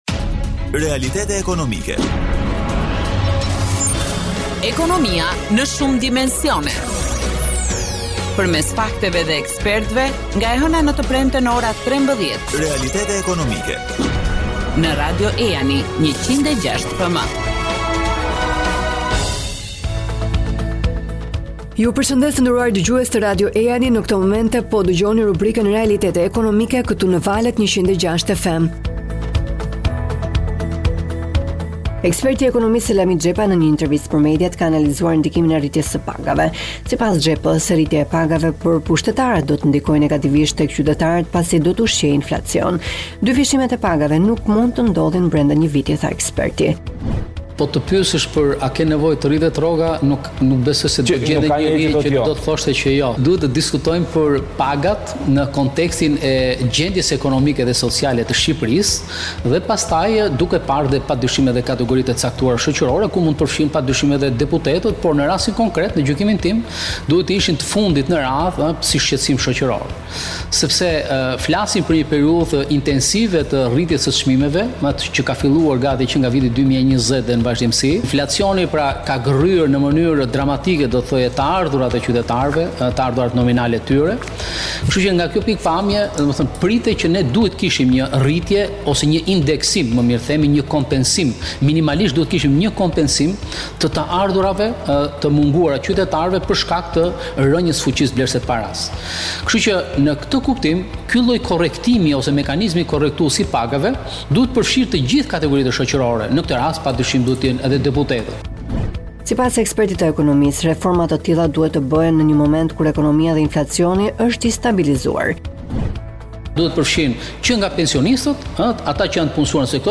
Shqipëria ka bërë hapa pozitivë në linjë me rregullimet evropiane, aktet e reja ligjore, të cilat kanë sjellë shumëllojshmëri në ofrimin e shërbimeve të investimit, mundësi të zgjeruar ndërlidhjeje me tregjet globale, si edhe ofrimin e një game të gjerë të instrumenteve financiare. Në një intervistë për mediat